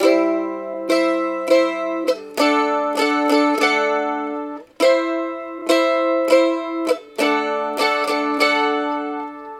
Mando 1 Strum C D 100 Bpm
描述：现场曼陀林，没有效果。这个循环可以与Mando 1的CD音符一起使用。100 bpm。